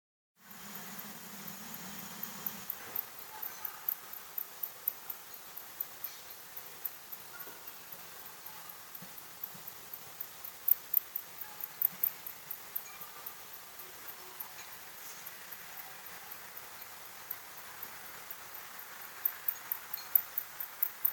Sikspārnis (nenoteikts), Vespertilionidae sp.
Ziņotāja saglabāts vietas nosaukumsPagalms
СтатусСлышен голос, крики
Mājas pagalmā jau kādu trešo vakaru lido dzīvnieks (iesp., sikspārnis) ar pārsteidzoši dzirdamu (un nedaudz kaitinošu) saucienu. Ierakstot pagalma skaņas telefonā, un atverot audio failu (pievienots šeit) kā spektrogrammu Sonic Visualiser programmā, konstatēju, ka dzīvnieka sauciens ir ap 12-15kHz diapozonā (pievienoju šeit kā attēlu).
Pēc skaņas amplitūdas izmaiņas (gan klausoties ar ausi, gan pēc spektrogrammas) šķiet, ka tas pārvietojas pa pagalmu.
Jāpiemin, ka ieraksts veikts ar telefona mikrofonu, tādēļ frekvences virs 20 kHz netiek ierakstītas.